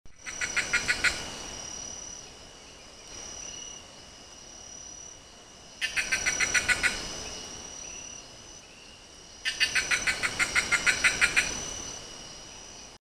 Urraca Común (Cyanocorax chrysops)
Nombre en inglés: Plush-crested Jay
Fase de la vida: Adulto
Localidad o área protegida: Refugio Privado de Vida Silvestre Yacutinga
Condición: Silvestre
Certeza: Observada, Vocalización Grabada